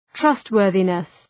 {‘trʌst,wɜ:rðınıs}